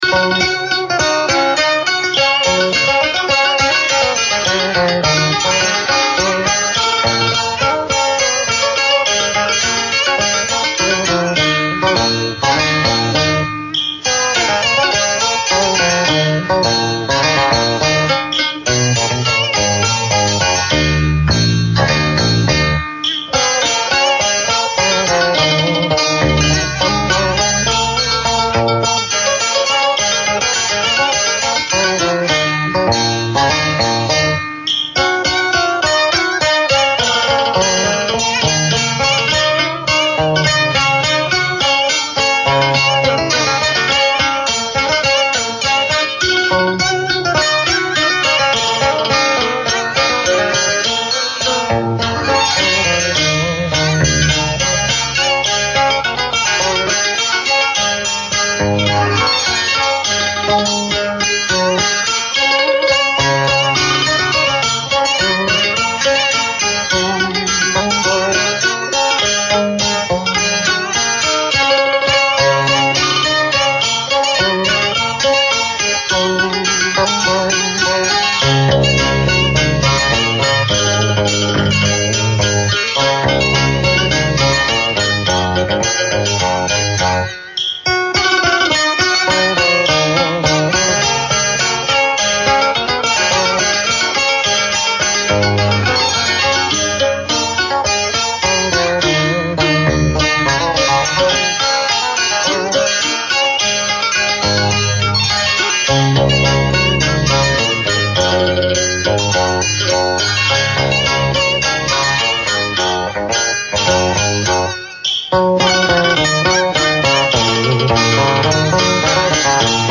Thể loại: Cải Lương https